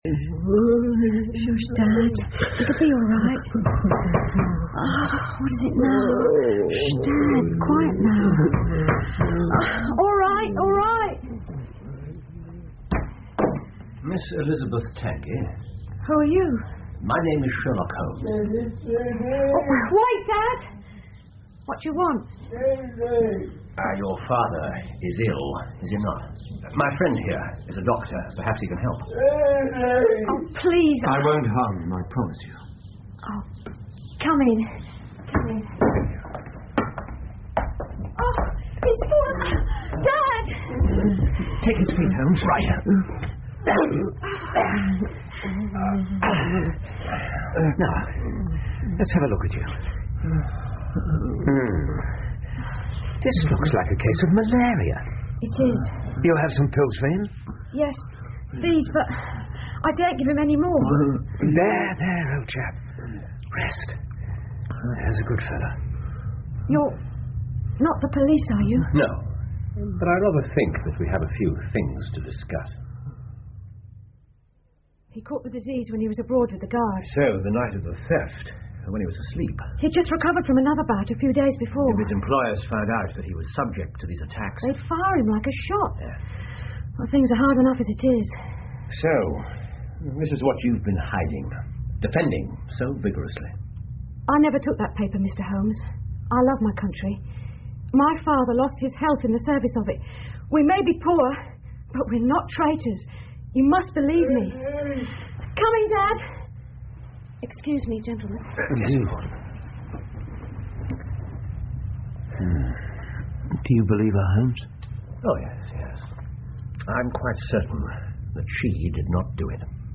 福尔摩斯广播剧 The Naval Treaty 6 听力文件下载—在线英语听力室